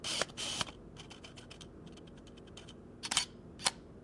DSL自动对焦快门
描述：佳能T2i数码单反相机的自动对焦和快门。用Rode NTG2话筒录制成Zoom H6 Handy Recorder。
标签： 自动对焦 相机 镜头 佳能 数码单反相机 快门
声道立体声